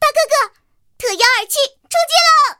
T-127出击语音.OGG